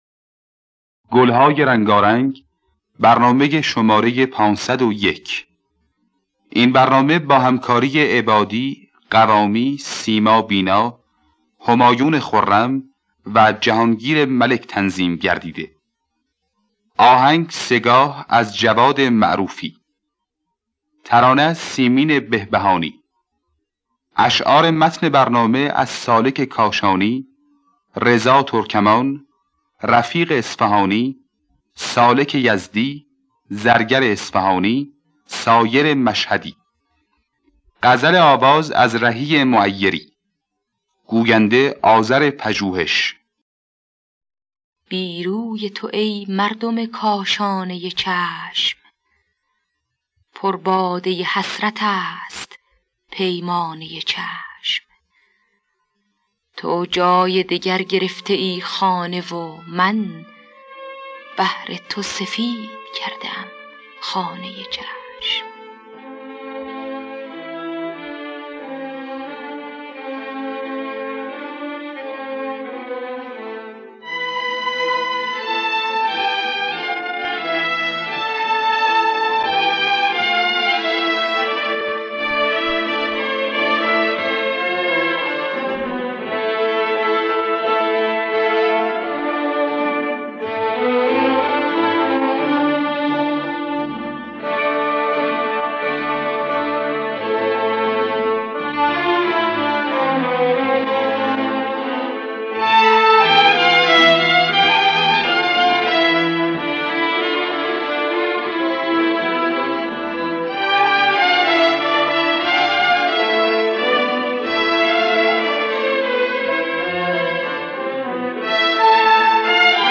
گلهای رنگارنگ ۵۰۱ - سه‌گاه
خوانندگان: سیما بینا حسین قوامی نوازندگان: همایون خرم جواد معروفی علی‌اکبر شهنازی احمد عبادی جهانگیر ملک